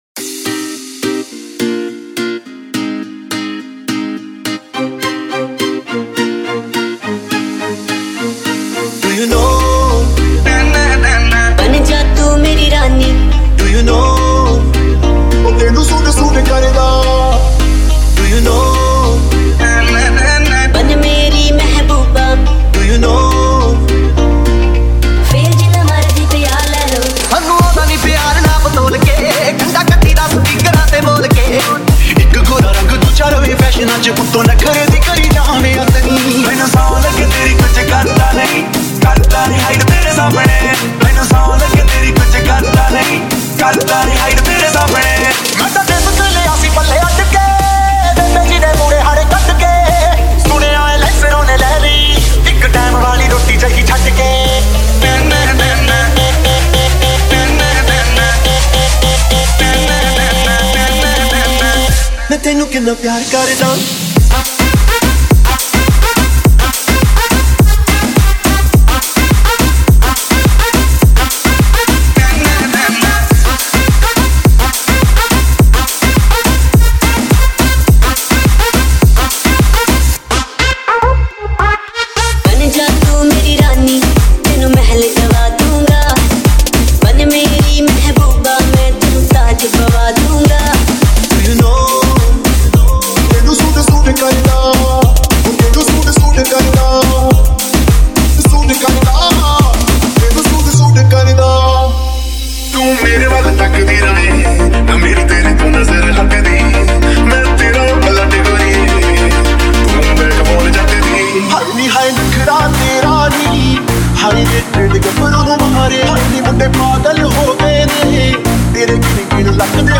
DJ Remix Mp3 Songs > Latest Single Dj Mixes